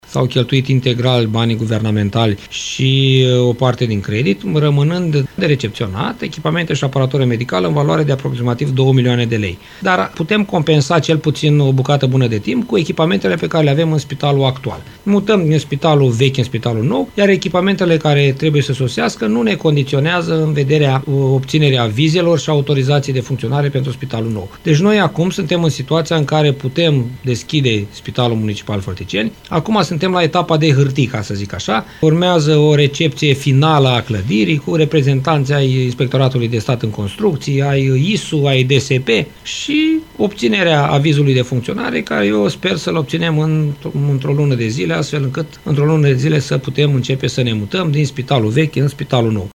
COMAN a declarat postului nostru de radio că – dintr-un total de 23 milioane 800 mii lei necesari pentru achiziția de echipamente – au fost primite de la Guvern 15 milioane lei, restul fiind suportaț de la bugetul local.